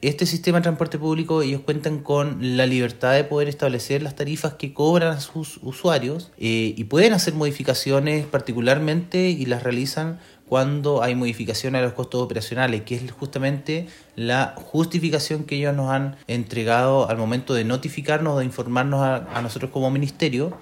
Al respecto, el seremi de Transporte, Pablo Joost, explicó que son los mismos dueños de las empresas de colectivos, quienes tienen la libertad de ajustar sus precios como mejor les parezca.
pablo-joost-seremi-transporte11.mp3